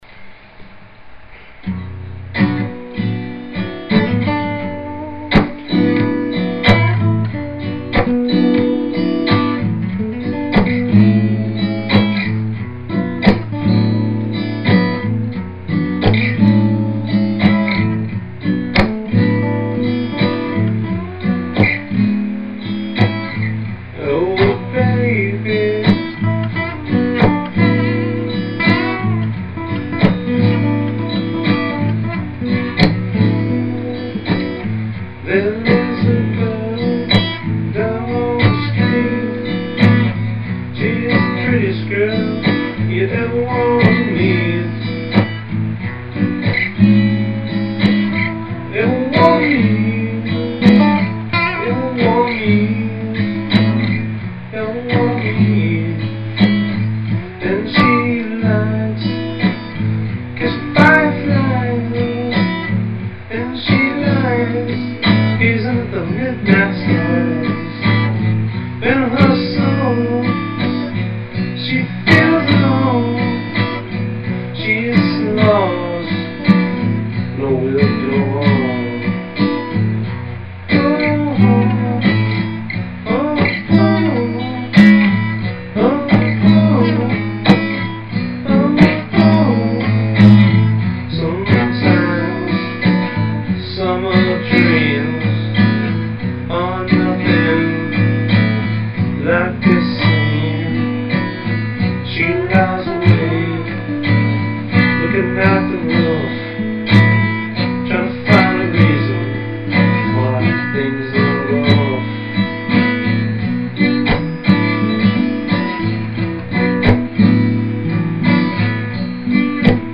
acoustic love song about a special girl